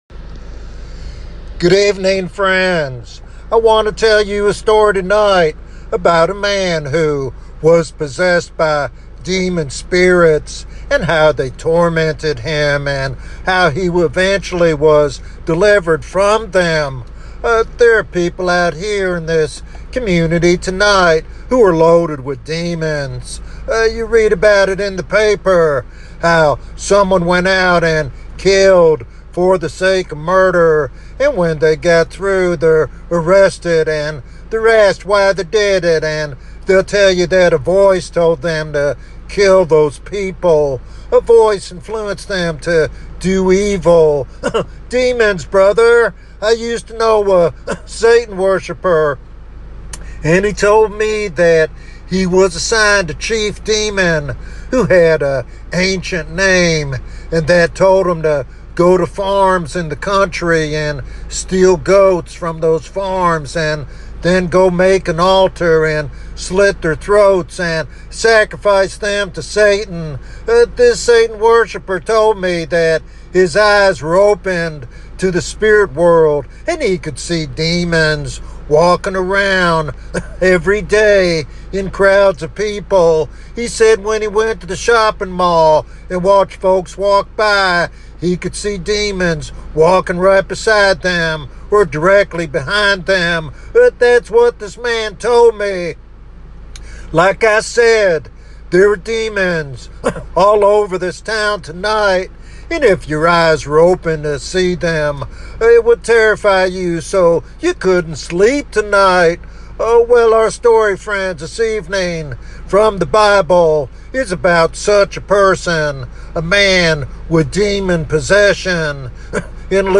This sermon challenges believers and seekers alike to examine their spiritual condition and embrace the transformative power of Jesus Christ.